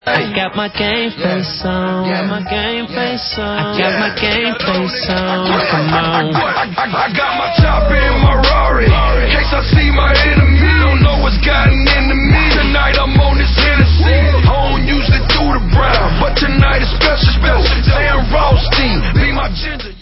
sledovat novinky v oddělení Pop/Rhytm & Blues